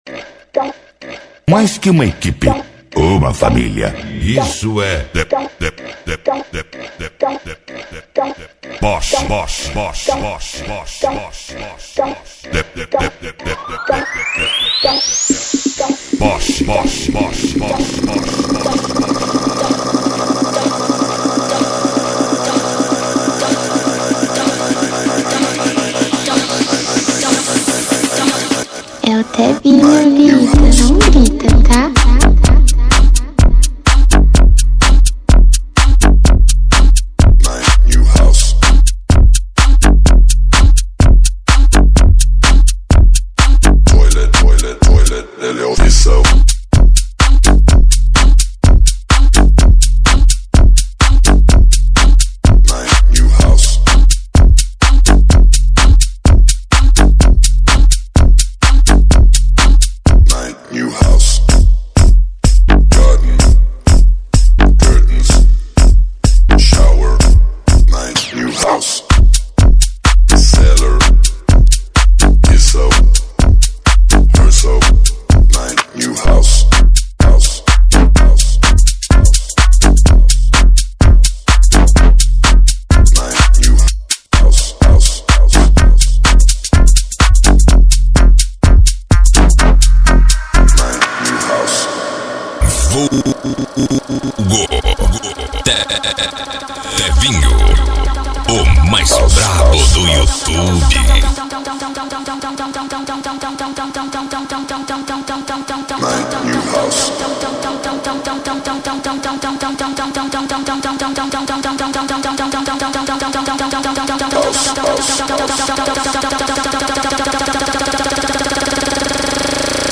2024-06-13 11:45:52 Gênero: MPB Views